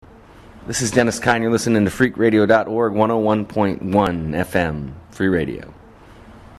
Station IDs for Free Radio Santa Cruz